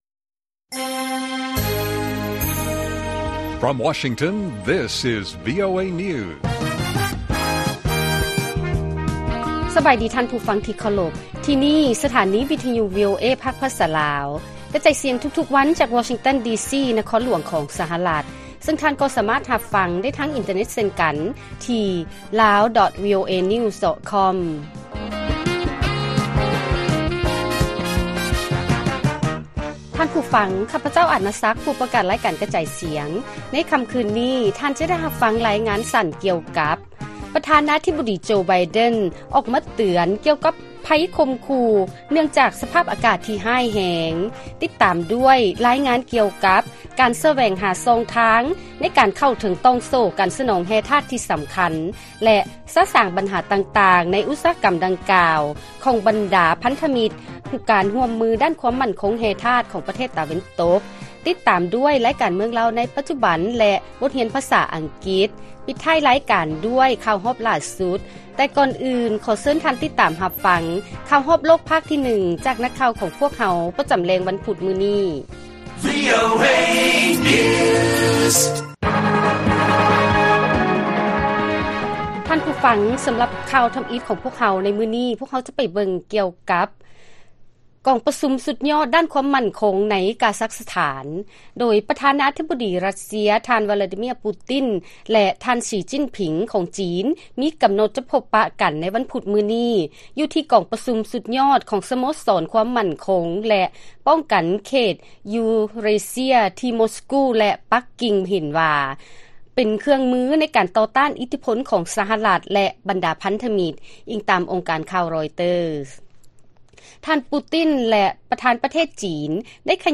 ລາຍການກະຈາຍສຽງຂອງວີໂອເອ ລາວ: ປະທານາທິບໍດີ ໂຈ ໄບເດັນ ອອກມາເຕືອນກ່ຽວກັບໄພຂົ່ມຂູ້ເນື່ອງຈາກສະພາບອາກາດທີ່ຮ້າຍແຮງ